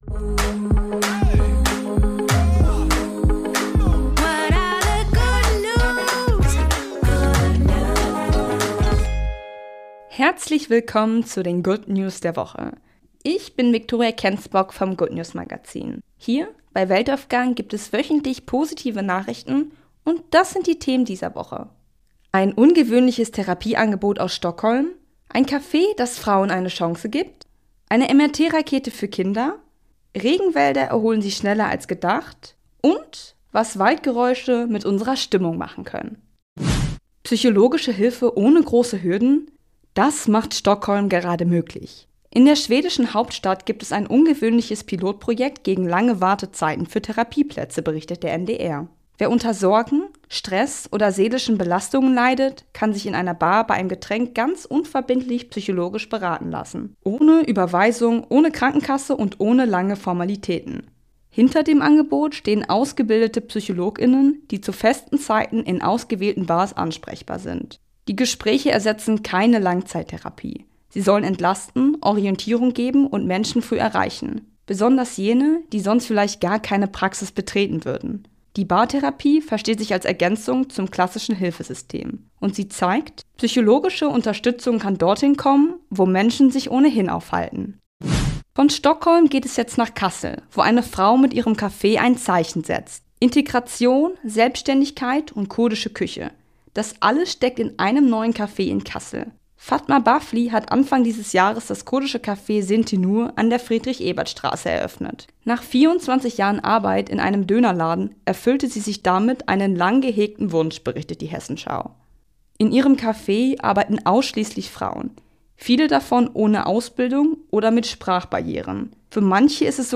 Jede Woche wählen wir aktuelle gute Nachrichten aus und tragen sie